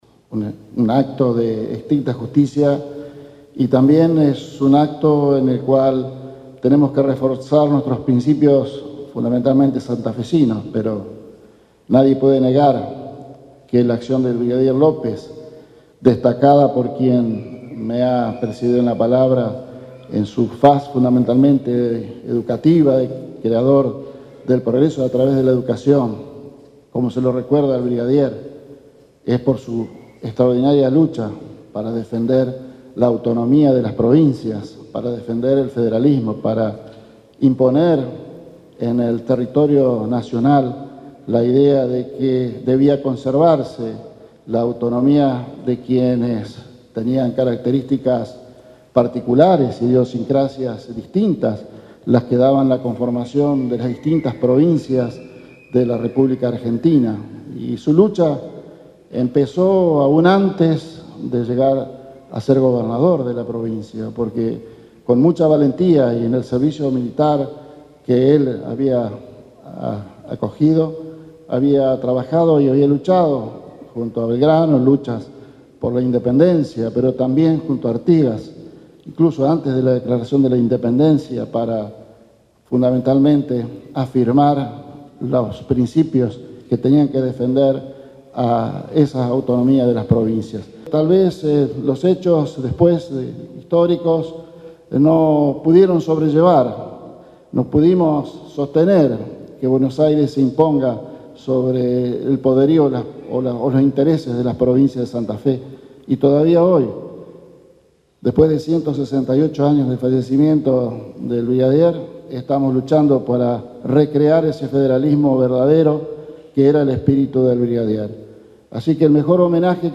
El vicegobernador encabezó este miércoles el acto recordatorio en el convento de San Francisco, en la capital santafesina.